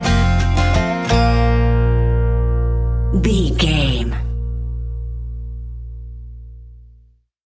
Ionian/Major
B♭
acoustic guitar
bass guitar
banjo